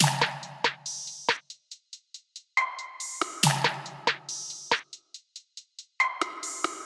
打击乐循环
描述：只是一个简单的鼓循环。
标签： 70 bpm RnB Loops Drum Loops 1.15 MB wav Key : Unknown
声道立体声